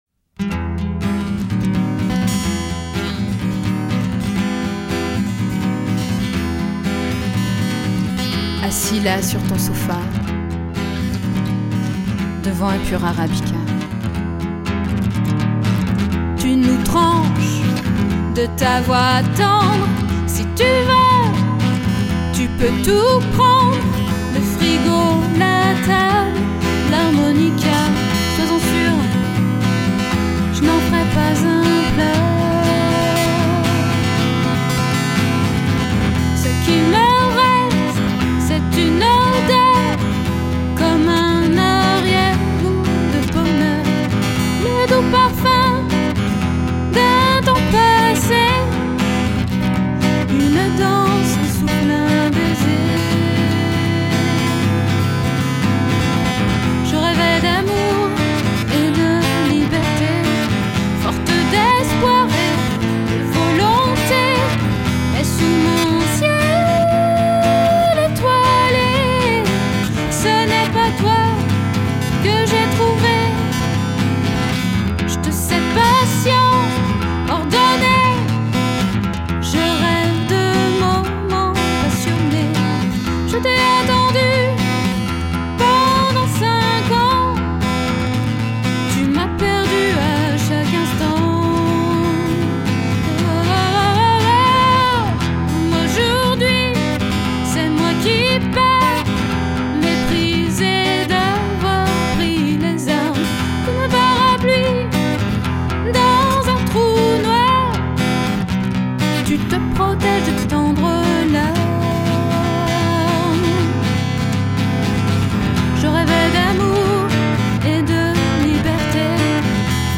chansons originales  françaises
à travers ses compositions POP / ROCK